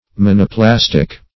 Search Result for " monoplastic" : The Collaborative International Dictionary of English v.0.48: Monoplastic \Mon`o*plas"tic\, a. [Mono- + -plastic.]